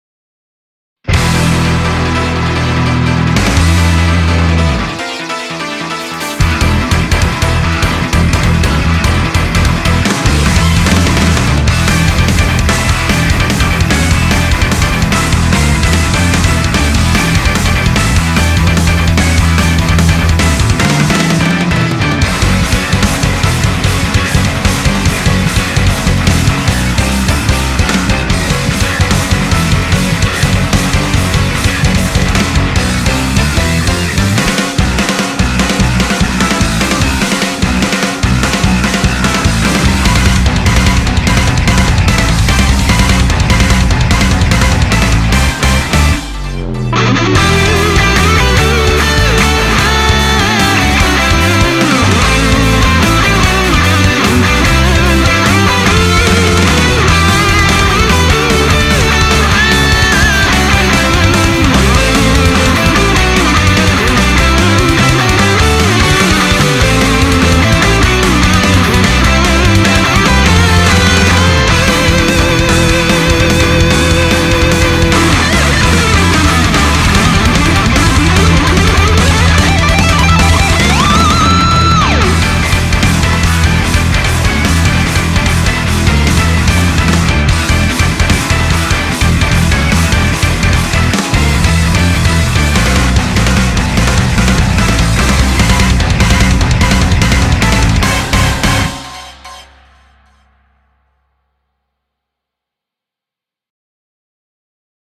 BPM148
Comments[METAL]